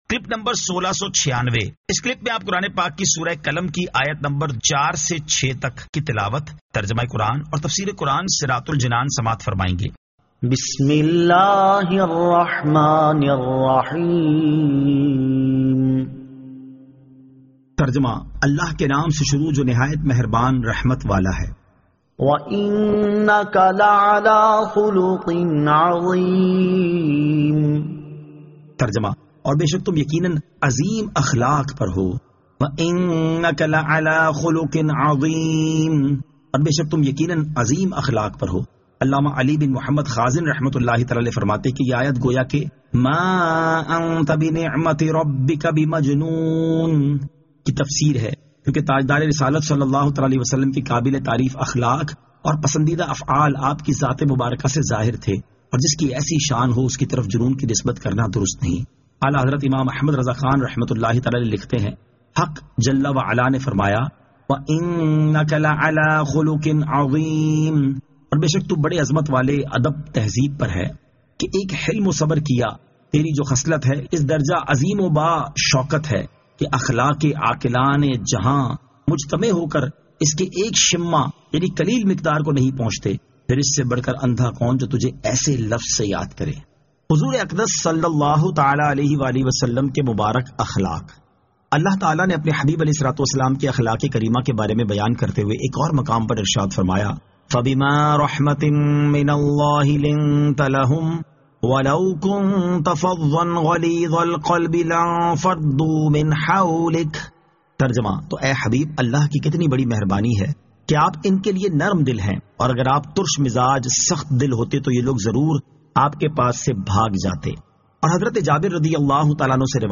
Surah Al-Qalam 04 To 06 Tilawat , Tarjama , Tafseer